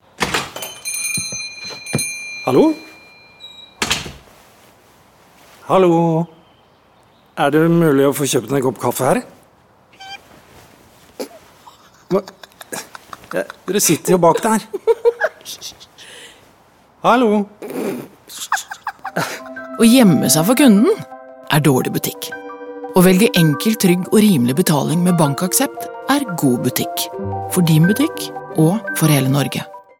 Vi liker spesielt godt den avslepne voice’en som svarer fint på dramatiseringen, fremfor en klassisk reklamevoice.